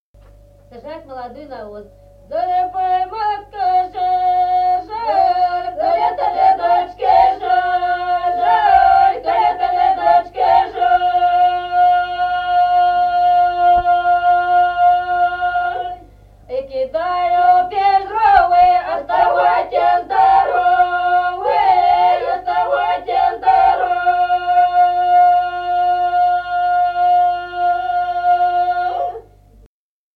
| diskname = Песни села Остроглядово.